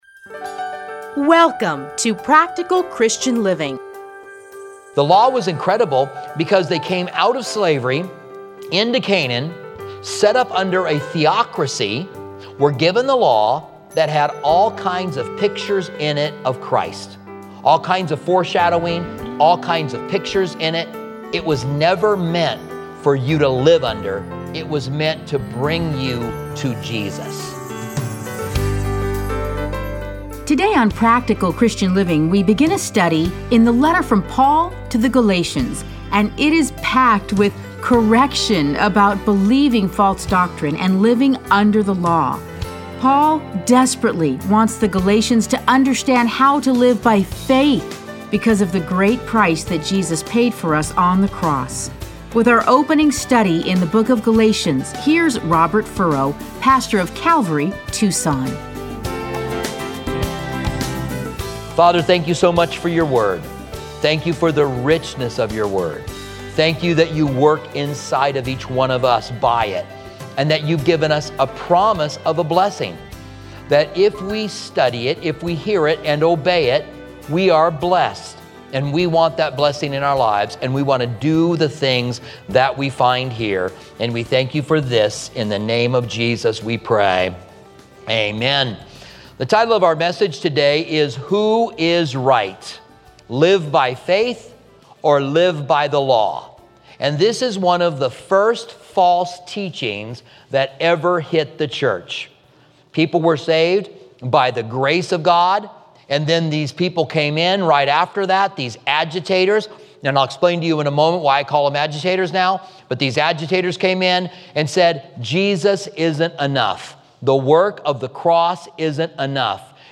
Listen to a teaching from Galatians 1:1-6.